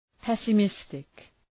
{,pesə’mıstık}